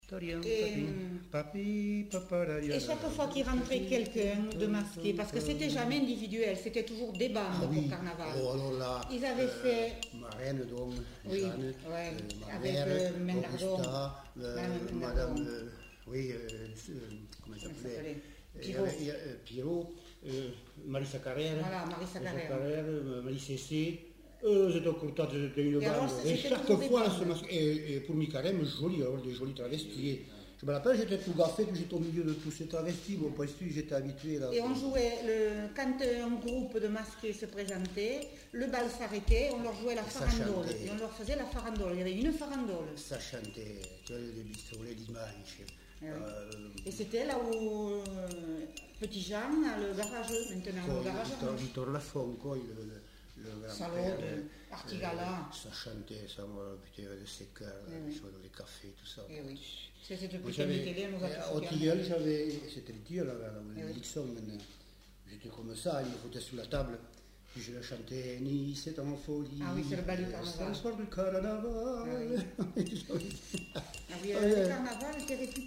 Air de carnaval (fredonné)